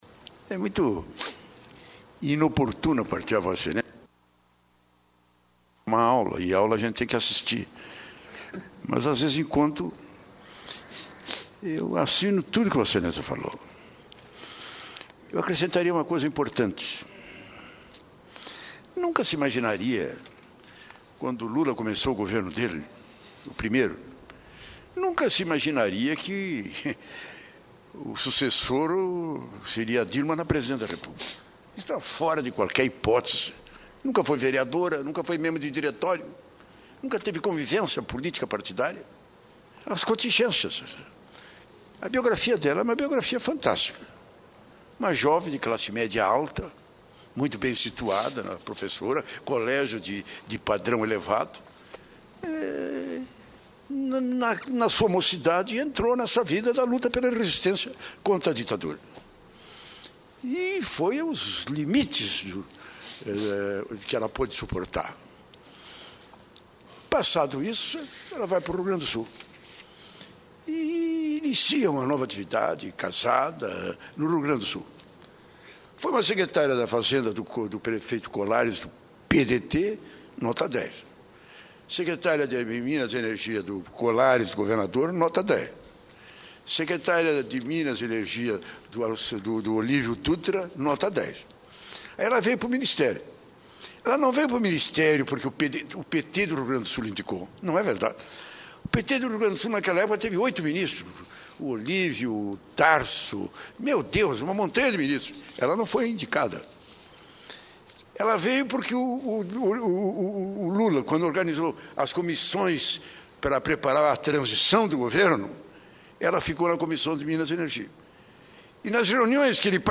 Em seu discurso nesta quinta-feira (8), o senador Cristovam Buarque (PDT-DF) considerou interessante a avaliação do jornalista Juan Arias, do jornal espanhol El Pais, de que no Brasil a população reage pouco em relação às denúncias de corrupção. Cristovam citou as passeatas realizadas por todo o país, no dia 7 de setembro, em prol da moralização na política e no governo.